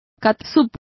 Complete with pronunciation of the translation of ketchup.